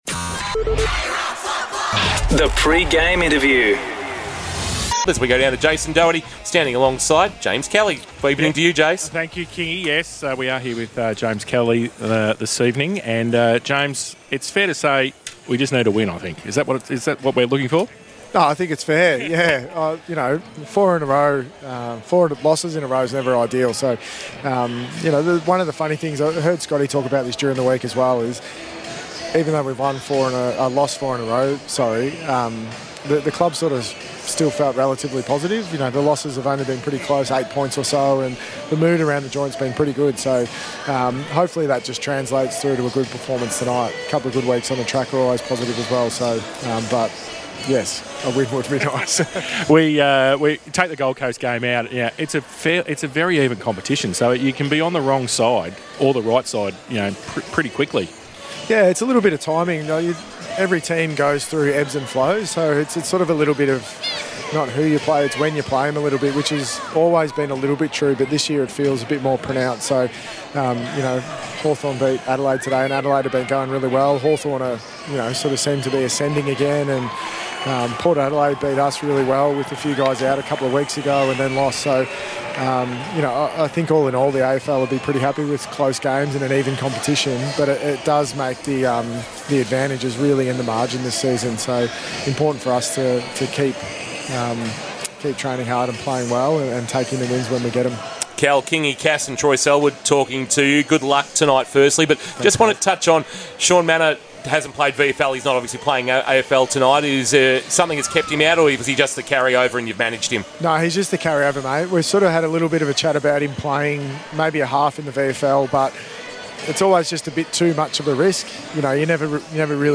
2024 - AFL - Round 12: Geelong vs. Richmond - Pre-match interview: James Kelly (Geelong assistant coach)